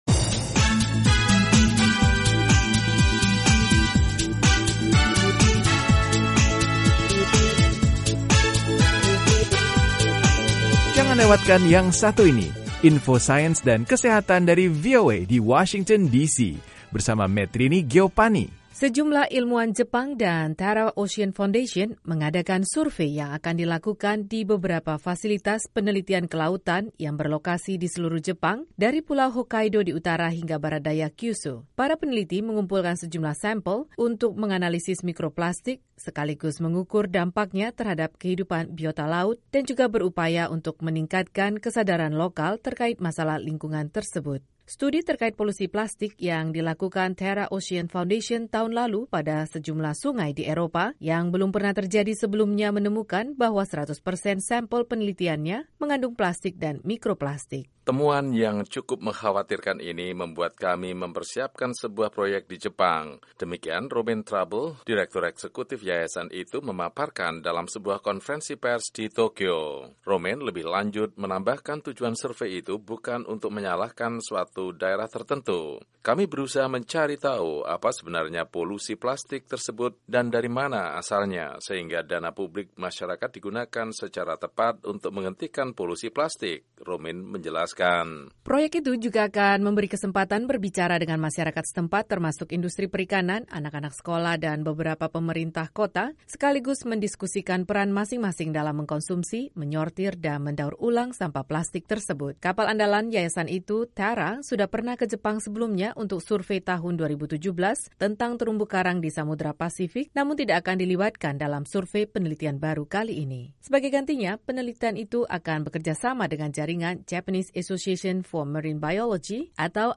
Para ilmuwan akan memulai survei dua tahun mikroplastik di perairan pantai Jepang mulai April, sebuah kelompok riset mengumumkan pada hari Rabu (29/1), dengan meningkatnya kekhawatiran terkait dampak plastik pada lautan. Laporan AFP berikut